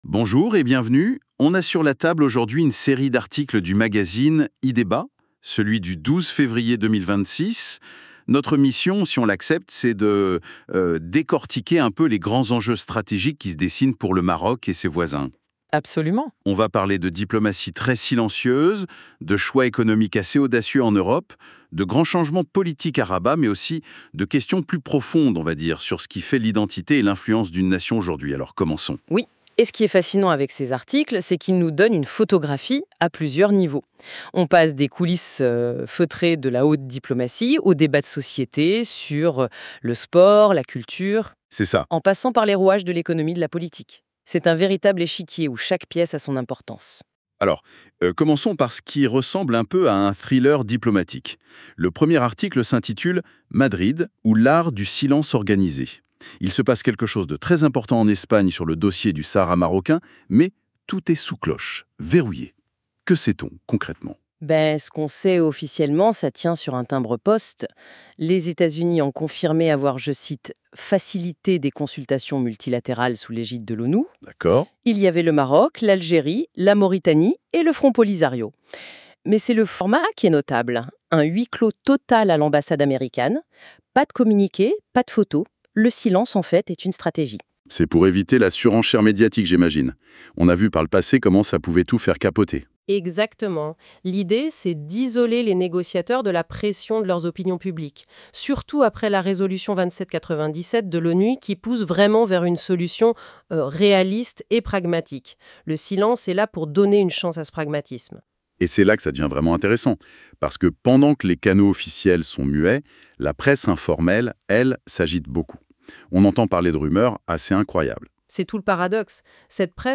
Débat en Podcast de la Web Radio R212 | Téléchargements | L'Opinion DJ Gen X,Y et Z
Les débats en podcast des chroniqueurs de la Web Radio R212 débattent de différents sujets d'actualité